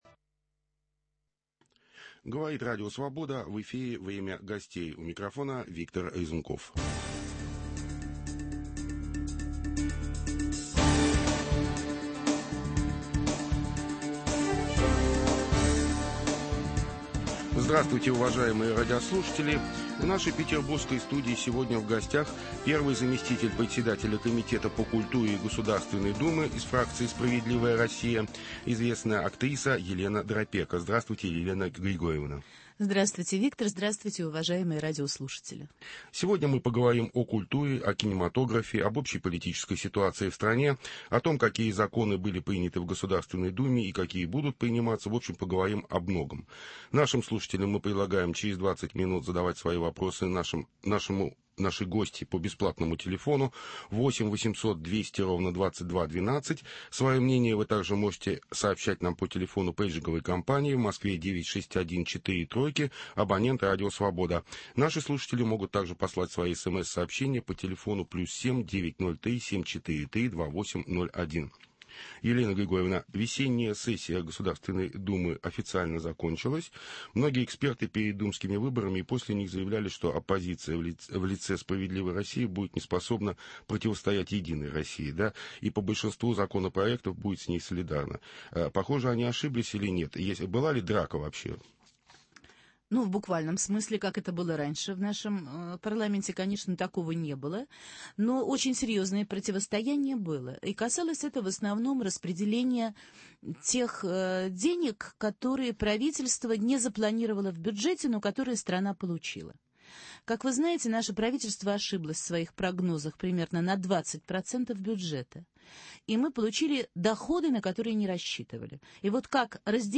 О культуре, кинематографе и российском законотворчестве беседуем с первым заместителем председателя Комитета по культуре Государственной думы /фракция "Справедливая Россия", актрисой Еленой Драпеко.